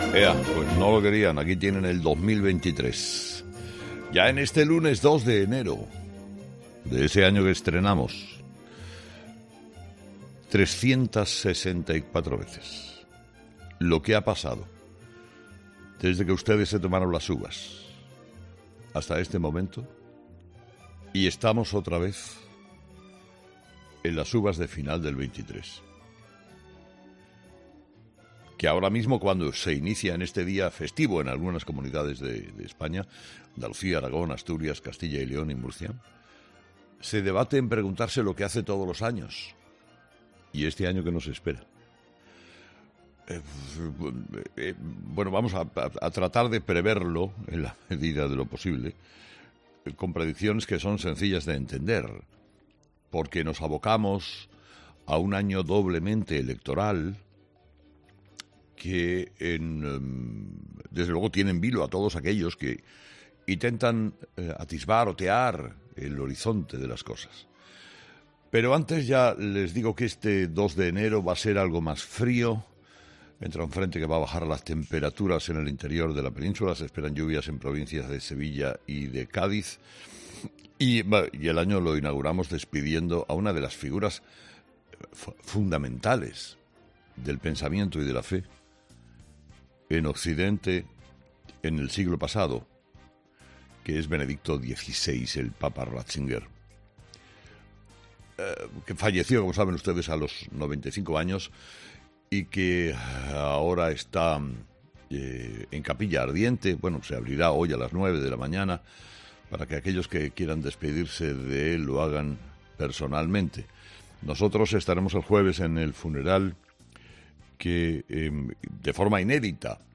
Carlos Herrera, director y presentador de ' Herrera en COPE', ha comenzado el programa de este lunes analizando las principales claves de la jornada, que pasan, entre otros asuntos, por la apertura de la capilla ardiente del Papa emérito, así como las claves políticas que van a marcar el 2023.